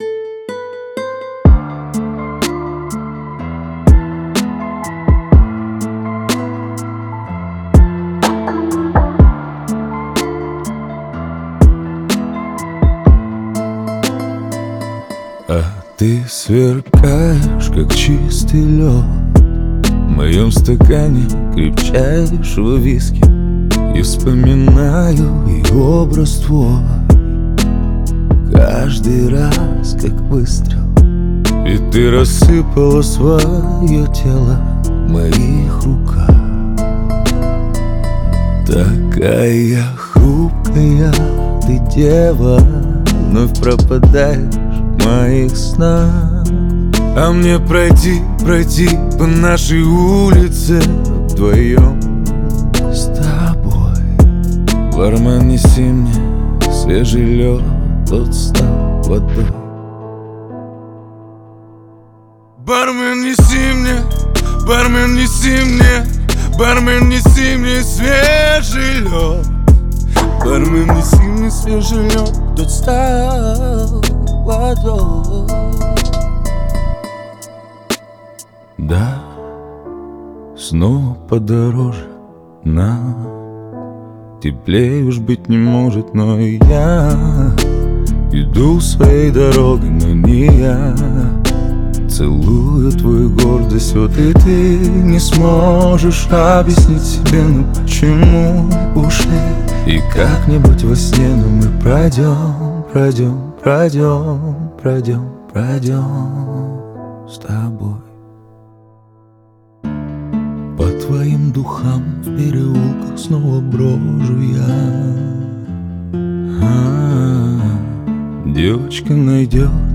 Грустные